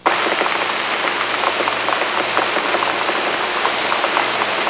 Rain on Roof Download
rainroof.mp3